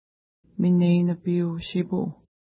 ID: 87 Longitude: -61.6209 Latitude: 52.8583 Pronunciation: mənej-nəpi:u-ʃi:pu: Translation: Burbot Lake River Official Name: Minipi River Feature: river Explanation: Named in reference to lake Minai-nipi (no 80) from which it flows.